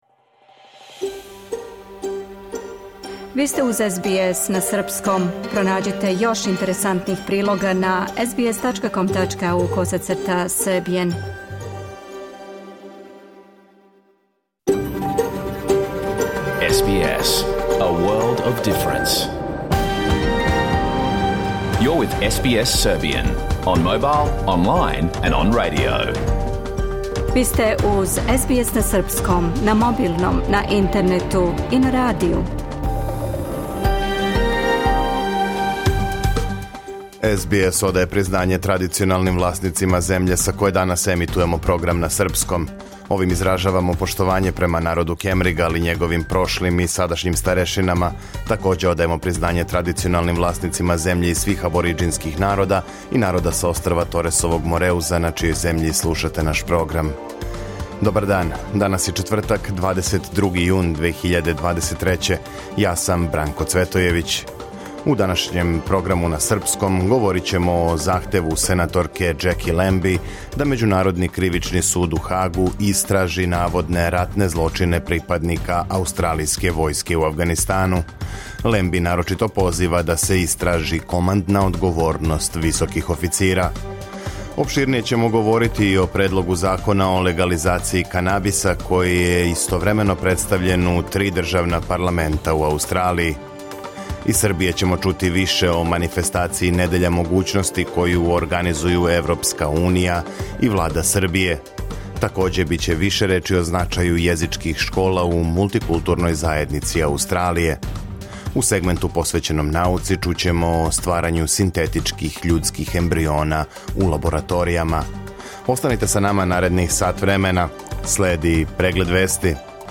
Програм емитован уживо 22. јуна 2023. године
Уколико сте пропустили данашњу емисију, можете је послушати у целини као подкаст, без реклама.